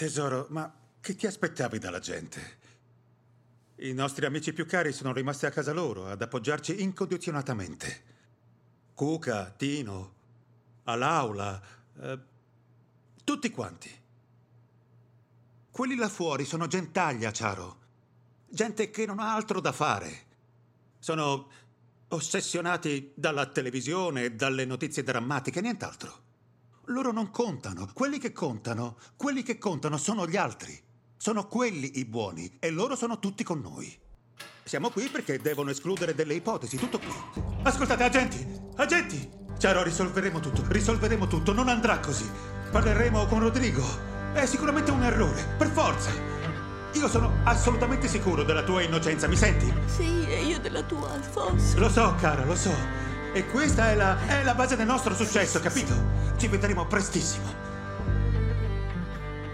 • Rainer Bock in "Hansel & Gretel cacciatori di streghe" (Sindaco Englemann)